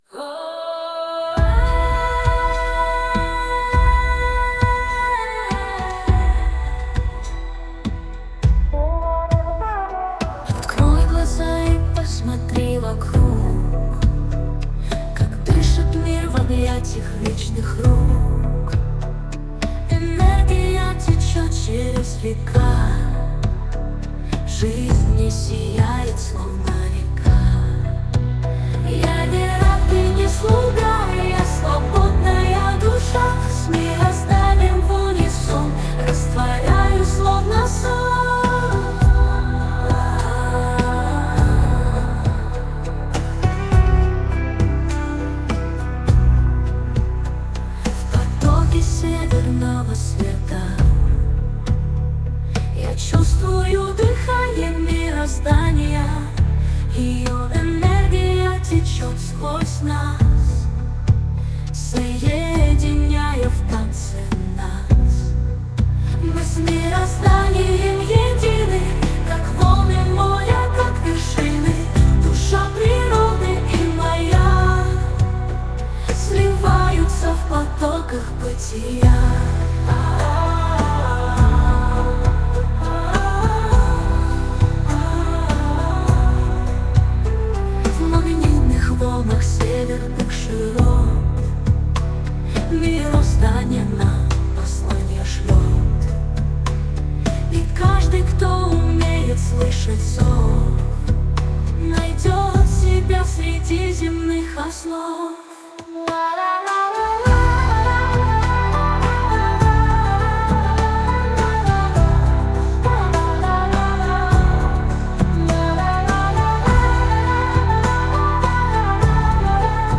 Жанр: Ethnic ambient